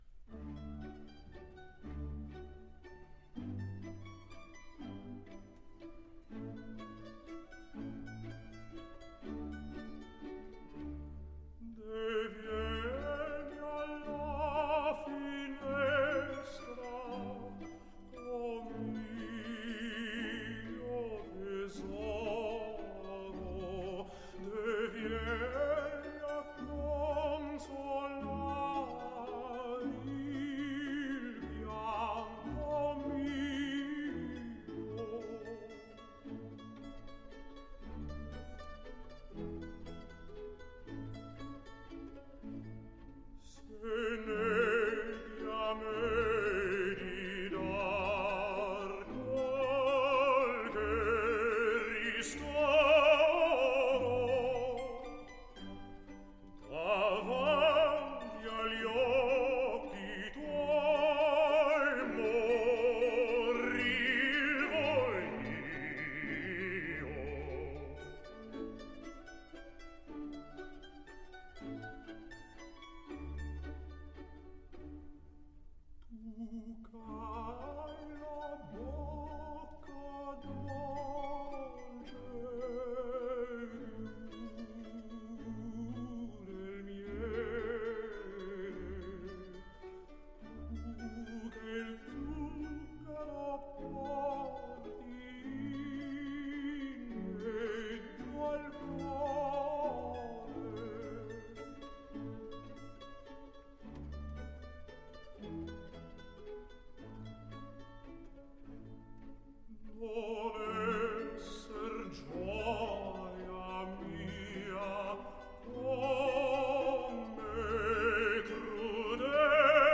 :-) This is Thomas Hampson, my favourite baritone of all time, singing my favourite Mozart opera aria of all time - Deh Vieni Alla Finestra from Don Giovanni.
Have a dash of opera to add to your nightcap of choice. :-) This is Thomas Hampson, my favourite baritone of all time, singing my favourite Mozart opera aria of all time - Deh Vieni Alla Finestra from Don Giovanni.